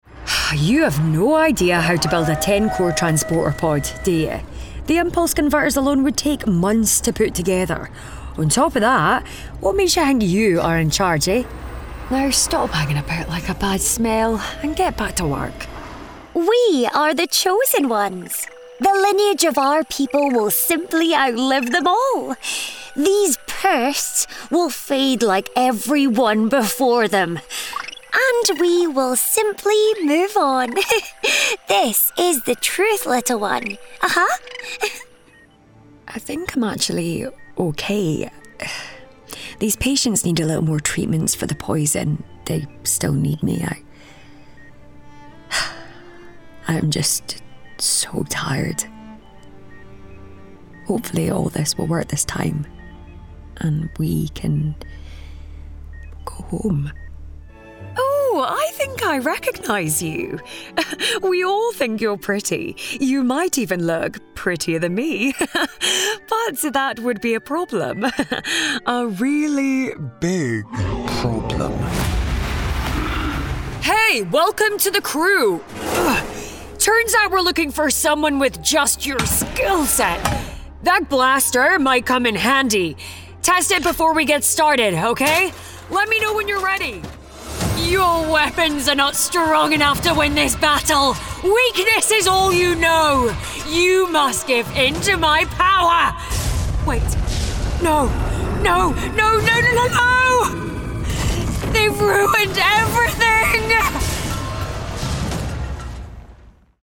Character Reel